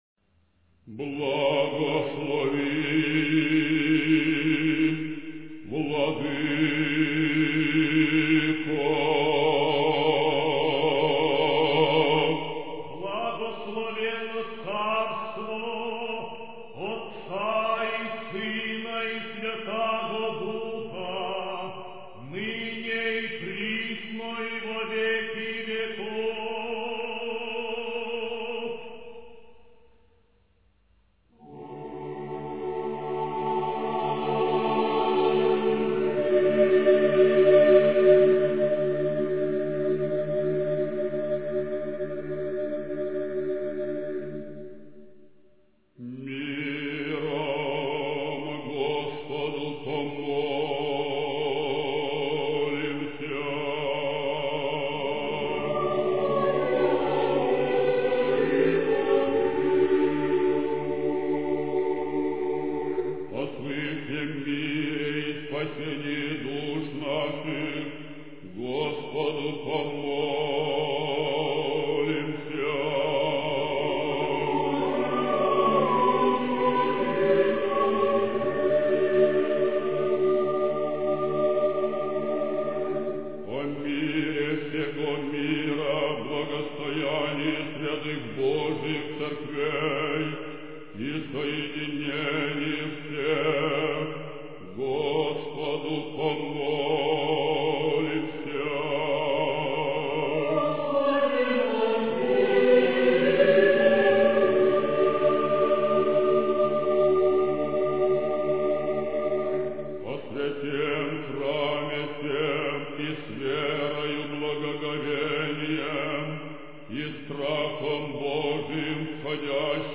Pravoslavná hudba východních křesťanů
Pravoslavné duchovní zpěvy mají často hymnický charakter, zesilující vlastní význam slov.